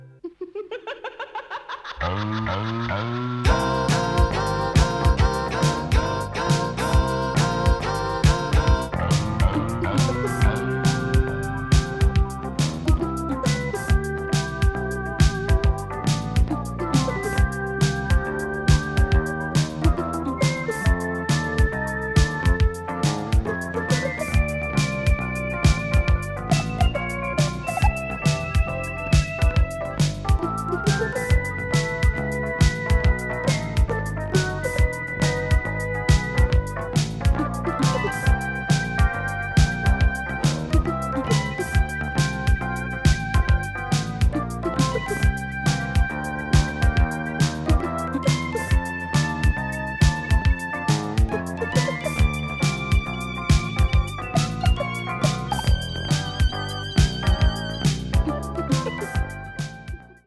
the usual eighties sound of synthesizers
a sort of new-age jazz mood
both are in pristine stereo sound.